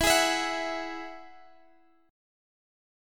Eadd9 Chord (page 2)
Listen to Eadd9 strummed